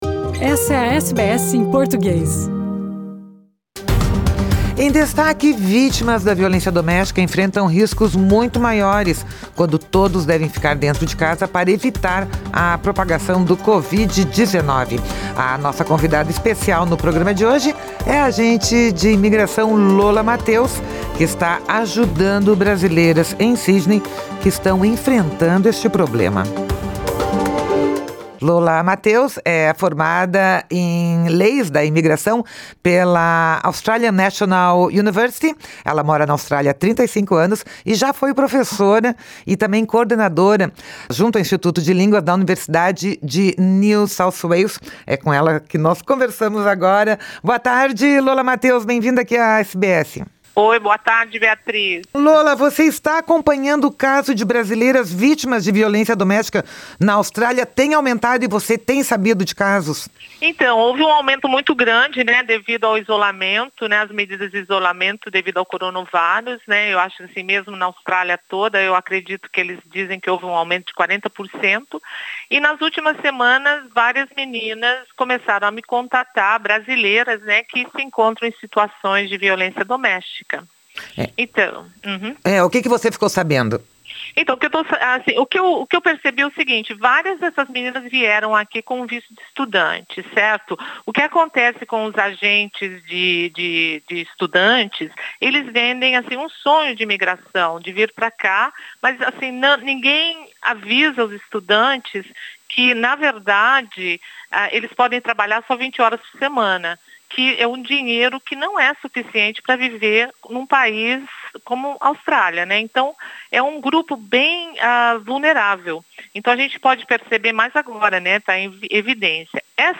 Nesta entrevista, ela relata que casos de agressão física acontecem e relembra que abuso psicológico também está enquadrado no Family Act do país, o que permite auxílios do governo.